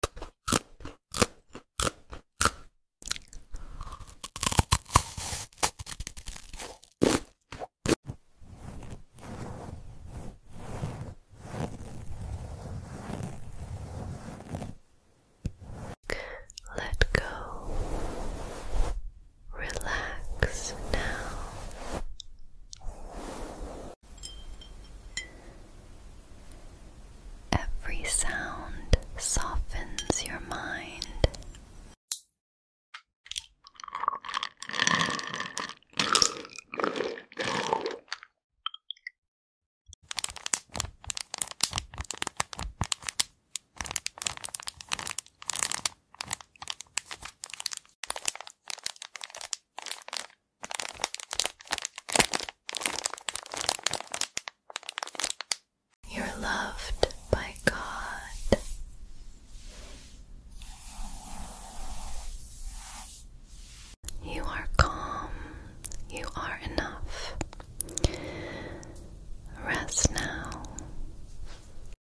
ASMR You Didn’t Know You Sound Effects Free Download